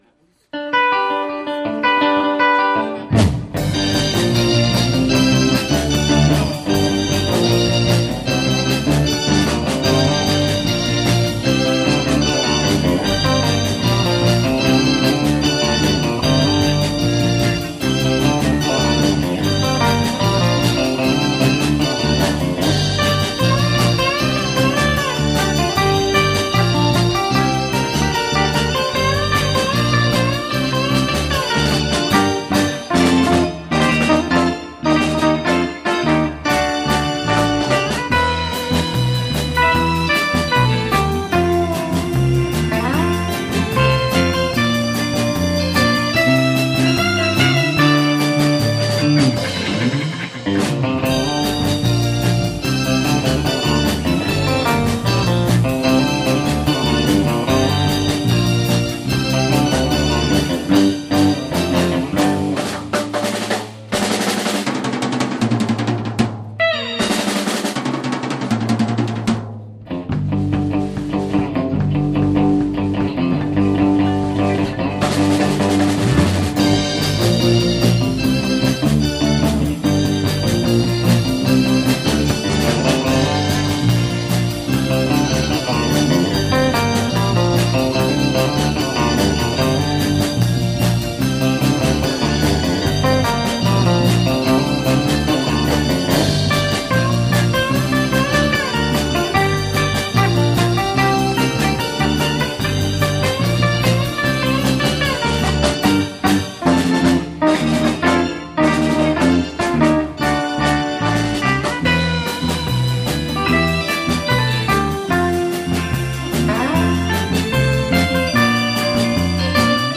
場所：ベンチャーズハウス「六絃」